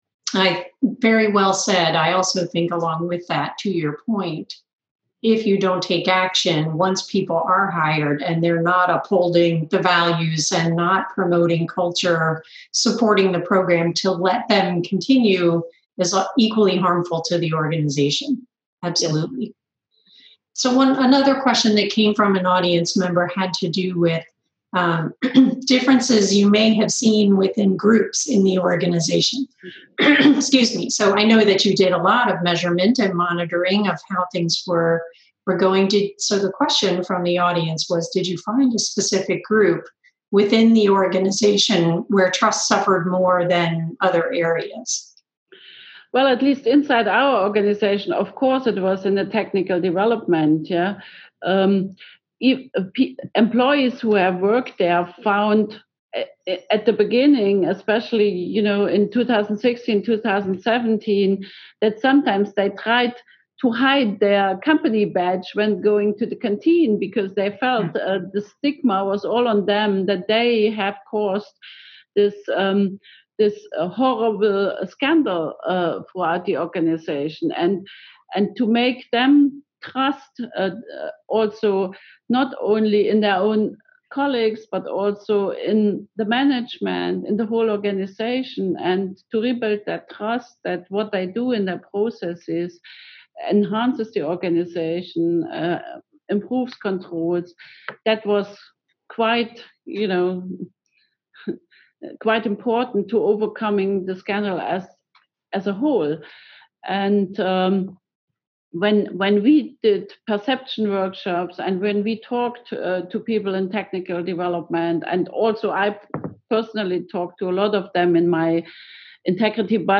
In part 2 of our 3-part interview series with the team behind Volkswagen AG’s Together4Integrity program